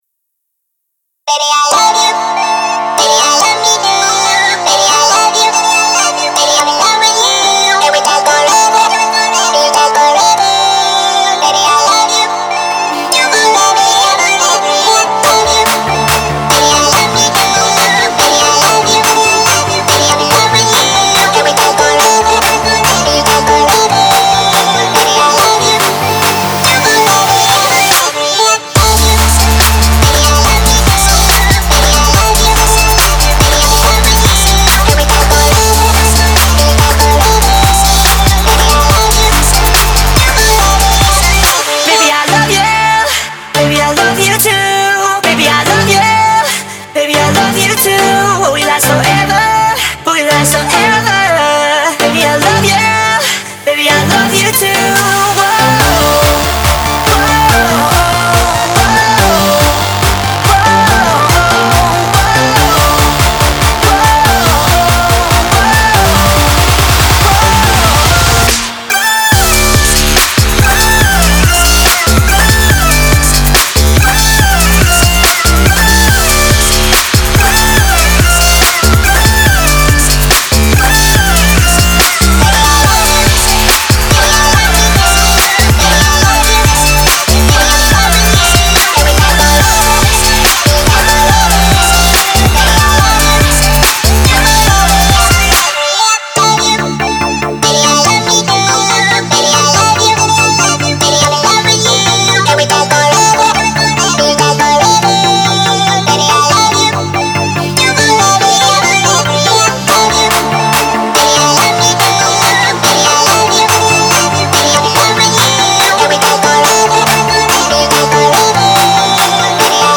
Жанр: Популярная музыка